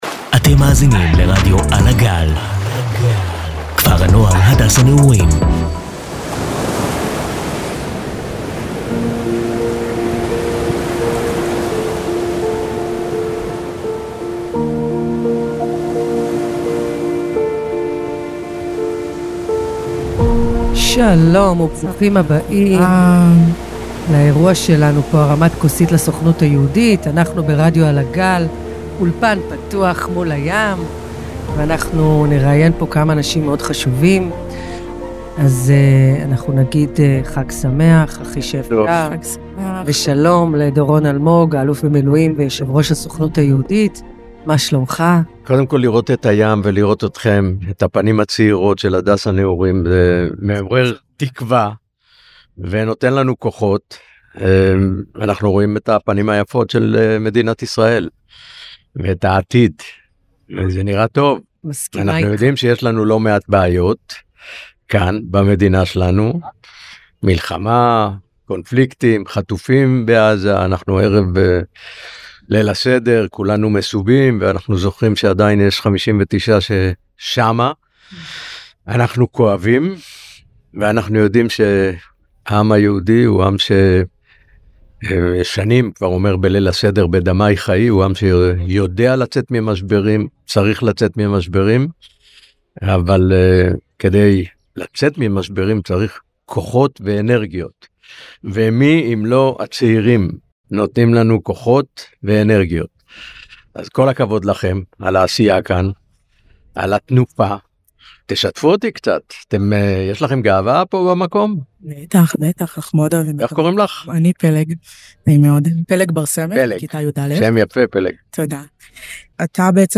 שידור חוץ מיוחד מאירוע פסח בכפר הנוער